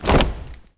AFROFEET 4-L.wav